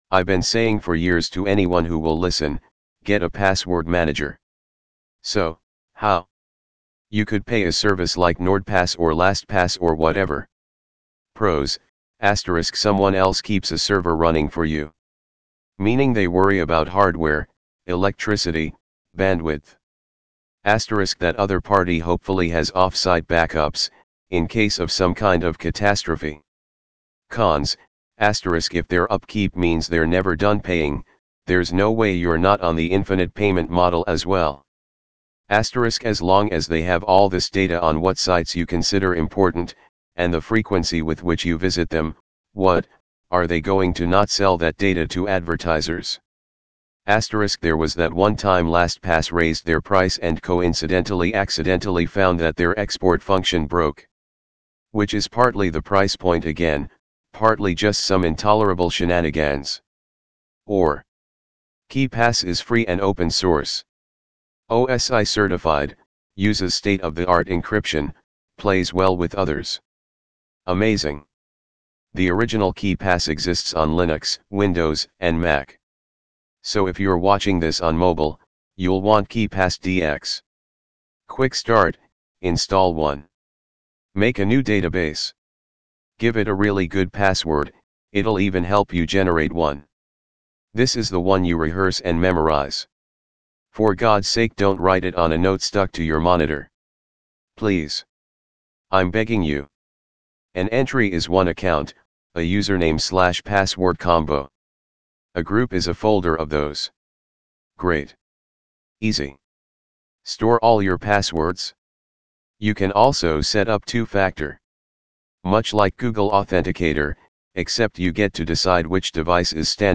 primaryVO.wav